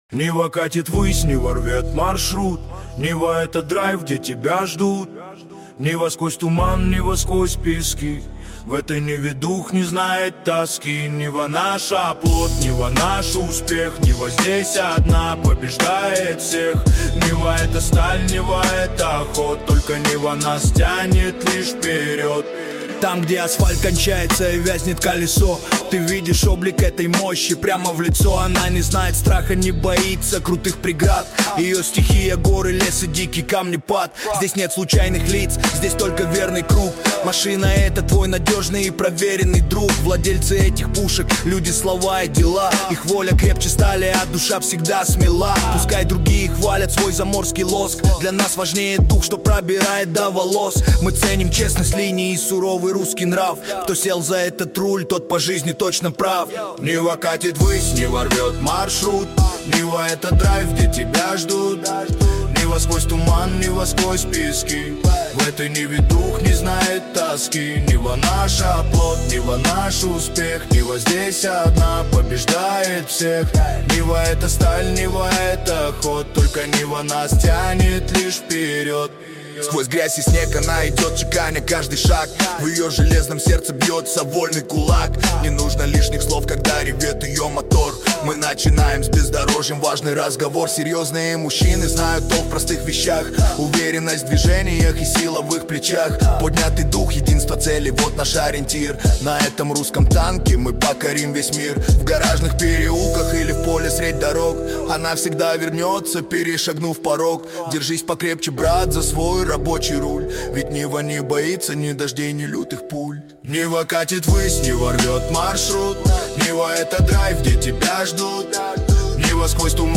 ДИНАМИЧНАЯ МУЗЫКА